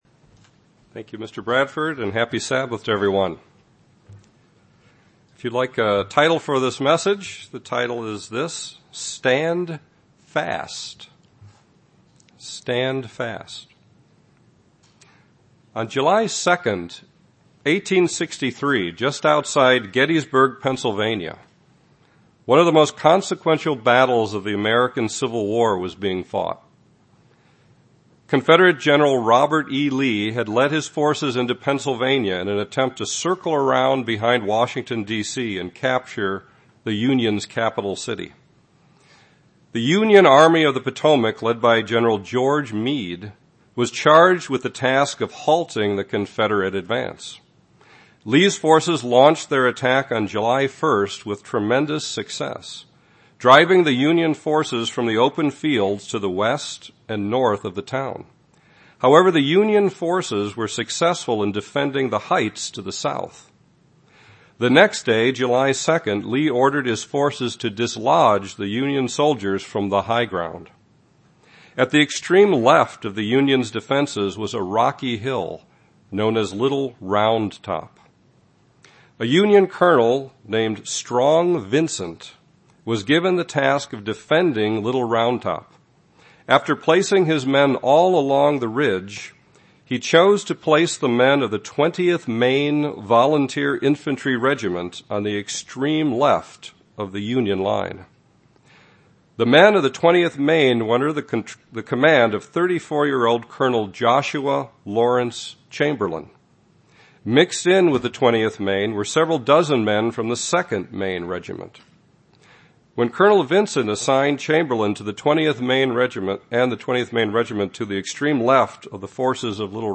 Has each of us truly made a personal commitment to stand our ground in the faith, regardless of whatever personal trials or public persecutions we may face in the coming months and years? This sermon cites numerous scriptures in which God exhorts His people to hold fast to Him and to His ways in order to guard against wickedness.